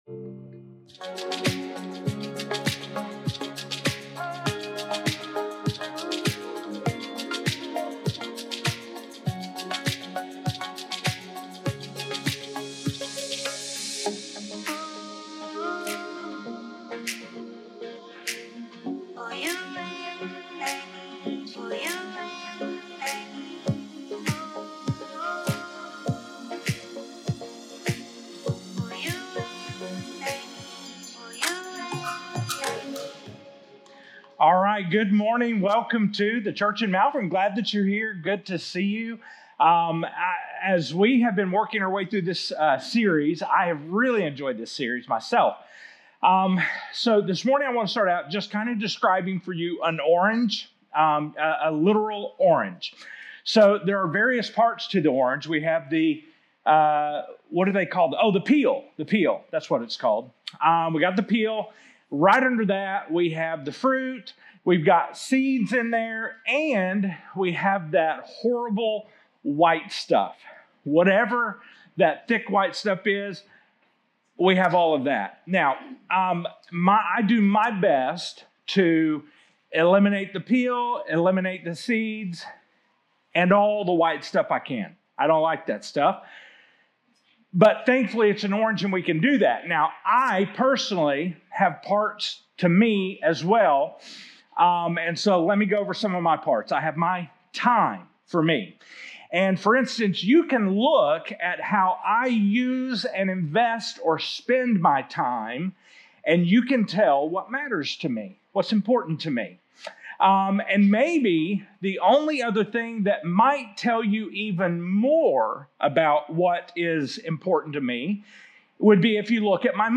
2025 Current Sermon 6 OF 8|JESUS 2.0 Worship... More than a song.